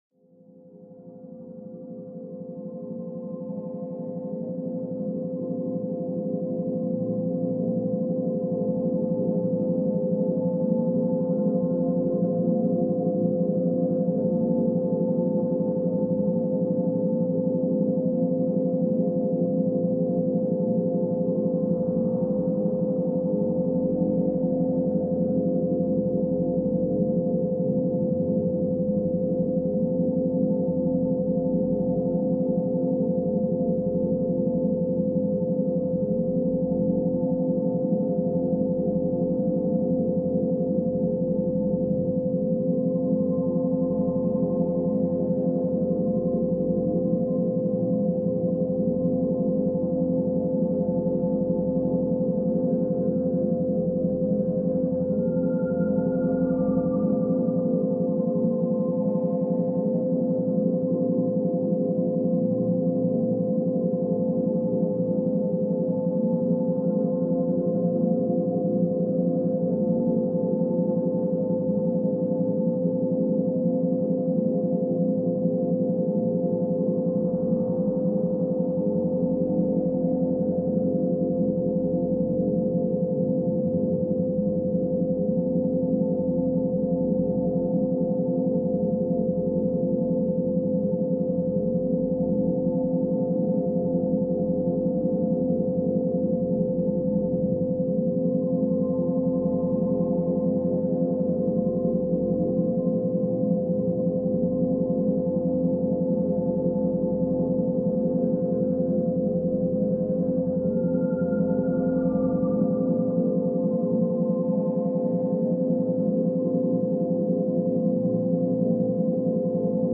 Méthode d’étude douce et calme · énergie de focus essentielle